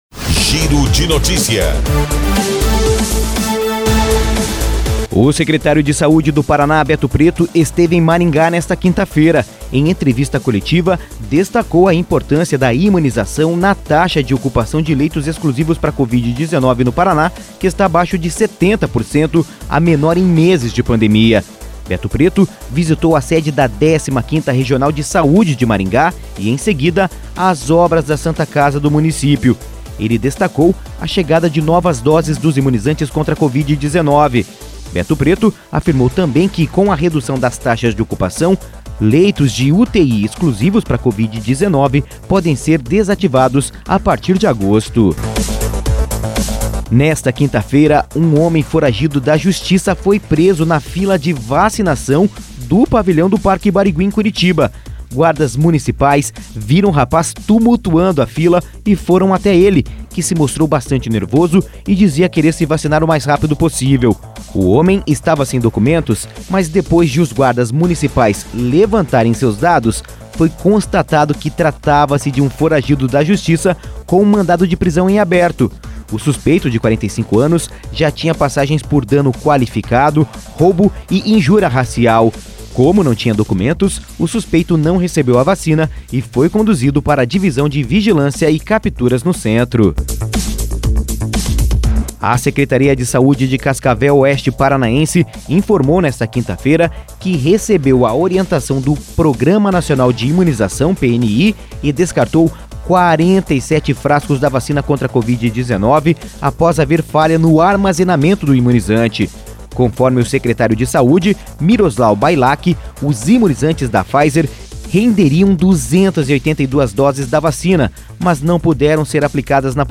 Giro de Notícias Manhã